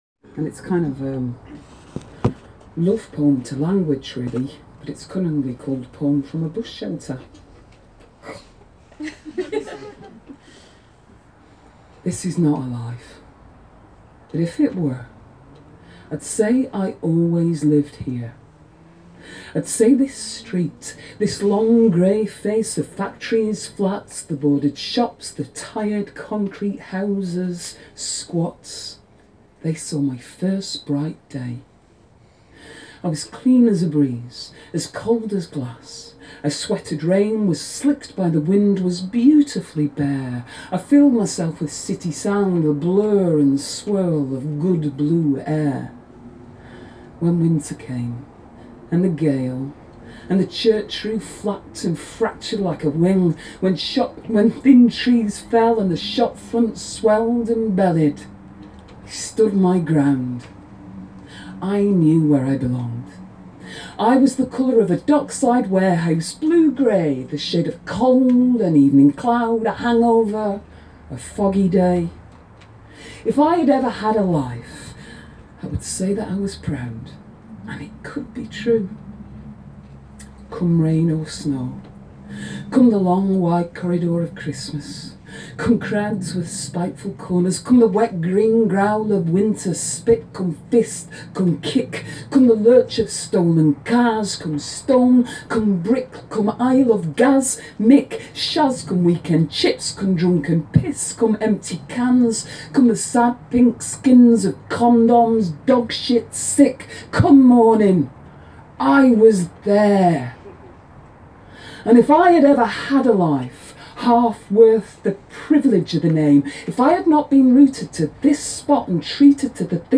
But there was serious whooping and cheering from the floor as the poets read in turn.